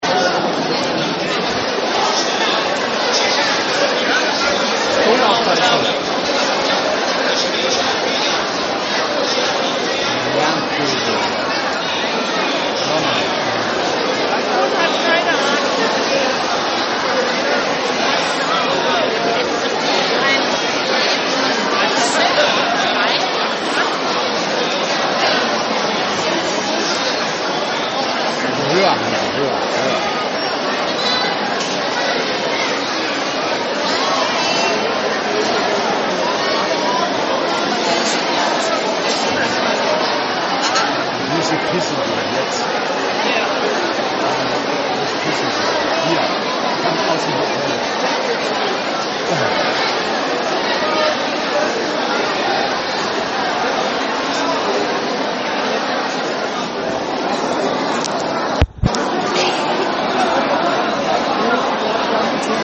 Akustisch sind alle 1,5 Mrd. Chinesen hier
Das ganze dauert etwa 45 Minuten und das in einer Halle mit einer Akustik weit über der des Frankfurter Bahnhofs. Eine Lautstärke die man sich nicht vorstellen kann.
Kurz vorm Hörsturz können wir endlich in die Reihe der glücklichen Ticketbesitzer und dürfen unseren Daumenabdruck abgeben.